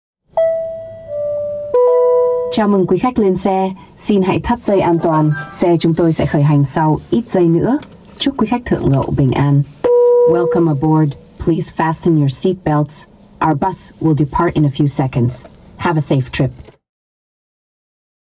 Tiếng Chào khởi động Ô tô CarPlay (Giọng nữ)
Thể loại: Tiếng chuông, còi
tieng-chao-khoi-dong-o-to-carplay-giong-nu-www_tiengdong_com.mp3